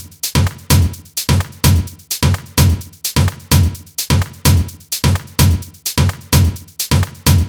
VDE 128BPM Notice Drums 5.wav